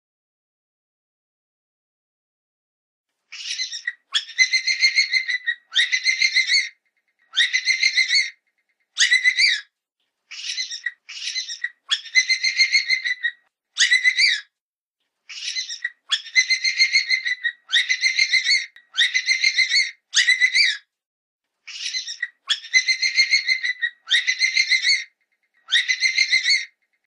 SONIDO DEL CONEJO.sonidos cortos de Animales.mp3